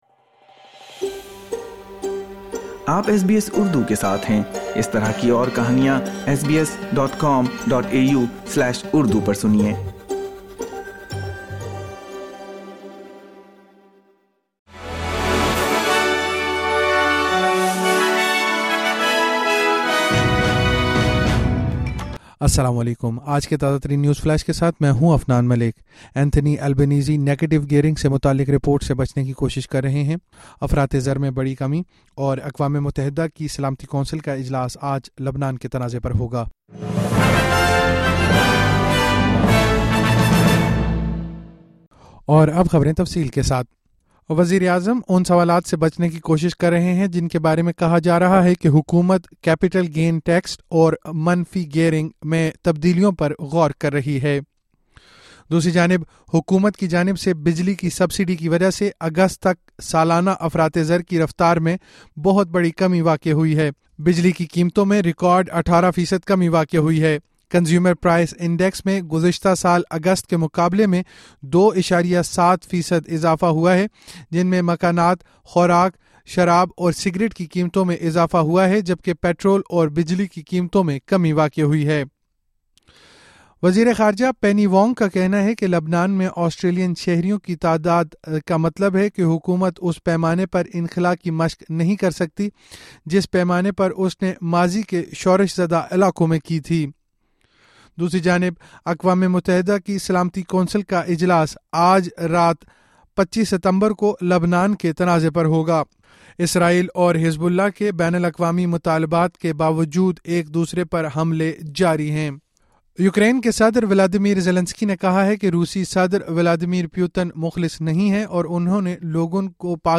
نیوز فلیش 25 ستمبر 2024: انتھونی البنیزی کا نیگیٹو گئیرنگ سے متعلق تبدیلیوں پر بات جیت سے گریز